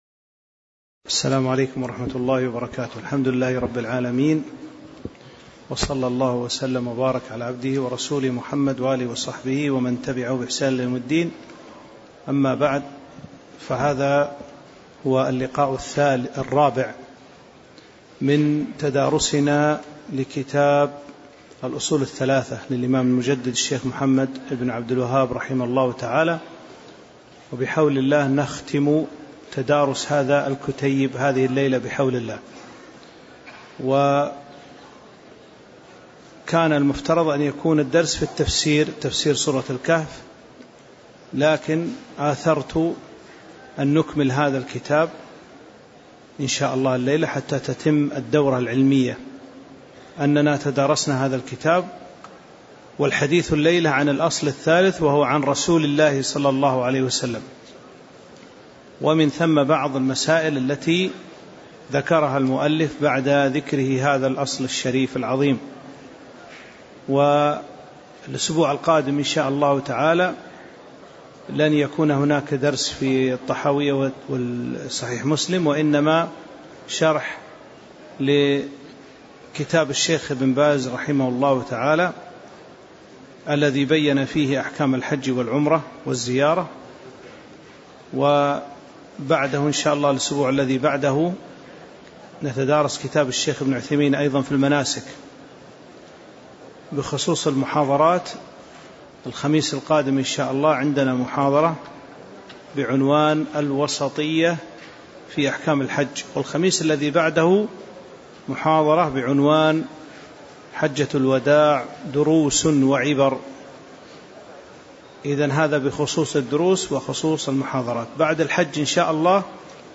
تاريخ النشر ١٩ ذو القعدة ١٤٤٤ هـ المكان: المسجد النبوي الشيخ